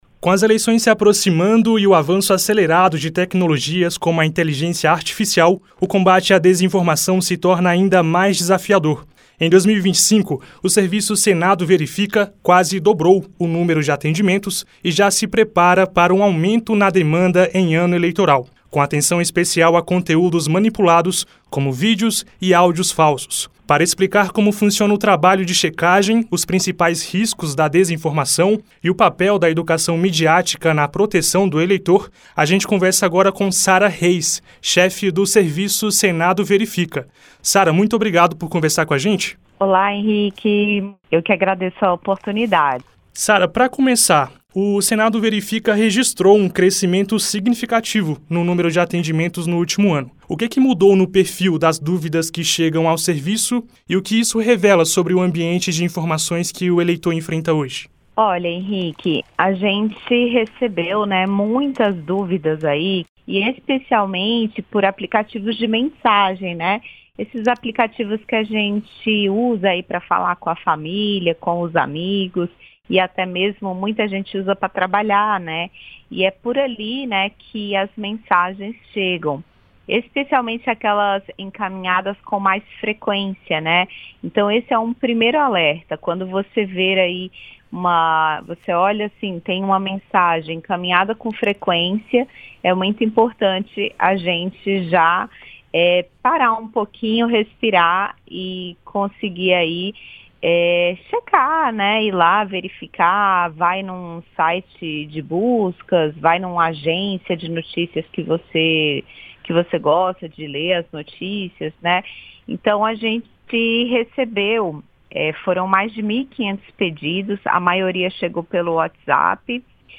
Entrevistas completas que deram origem às nossas reportagens e programas